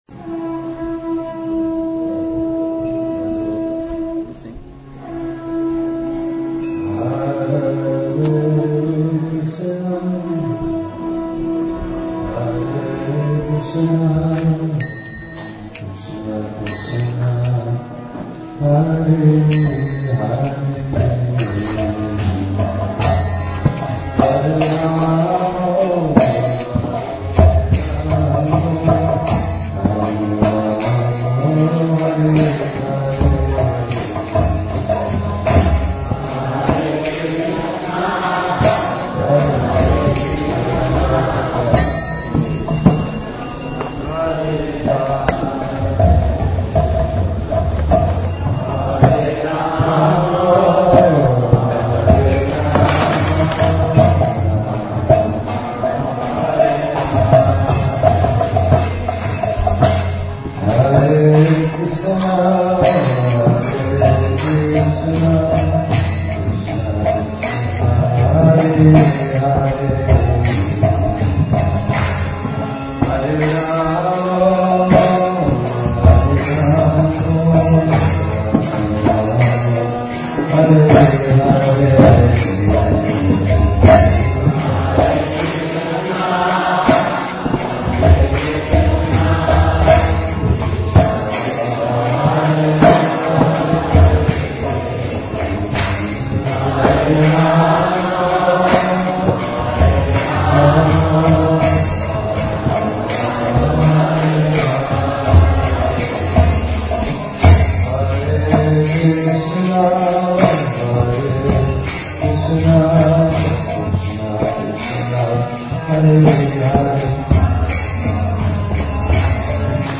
2008 September Namamrita Experience